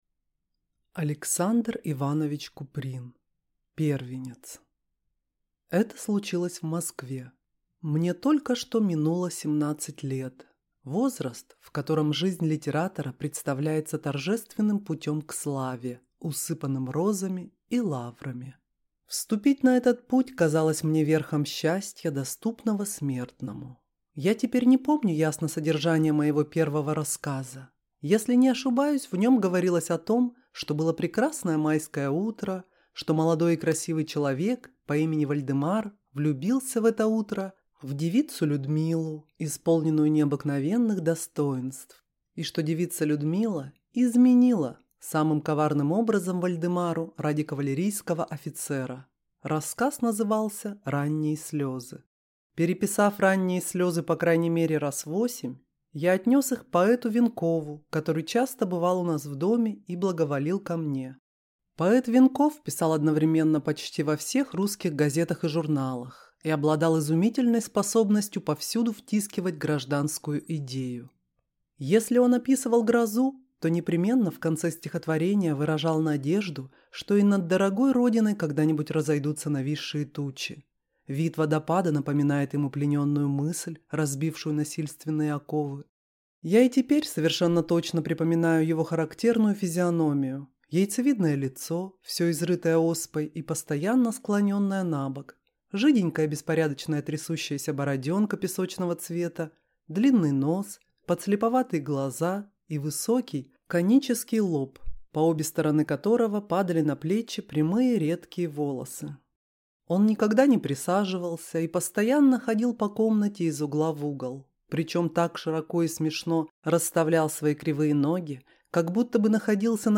Аудиокнига Первенец | Библиотека аудиокниг